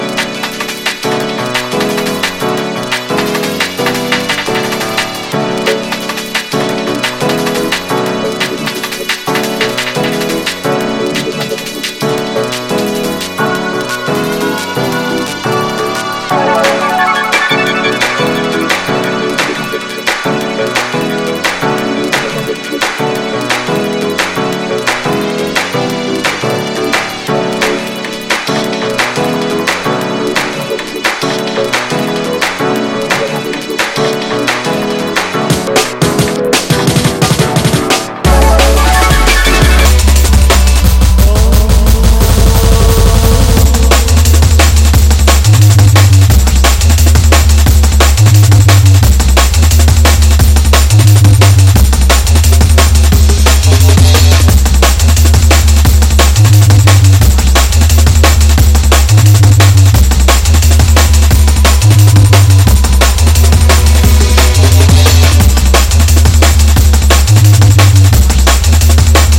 Surface marks and scratches cause noise on playback